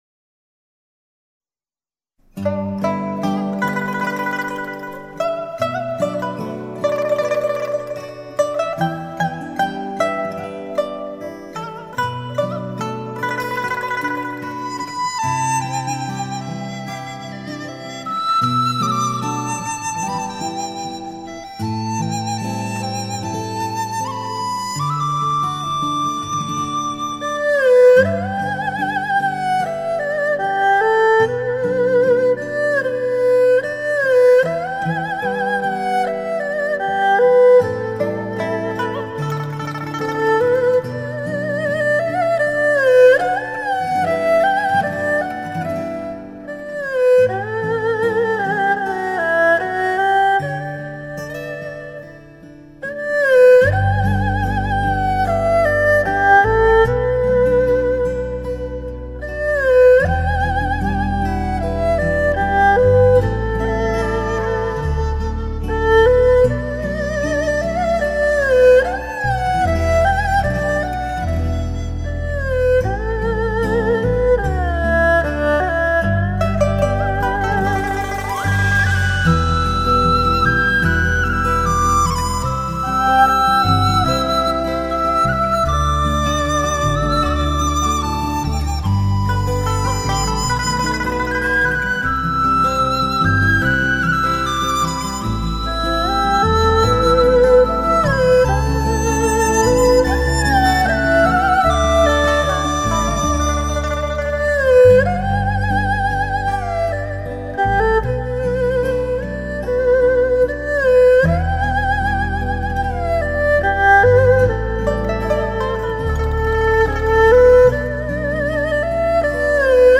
风格甚为古朴、儒雅
二胡音像“形态”丰满厚实，音色甜润醇和而且胆味浓郁，质感之真实犹如亲临录音现场。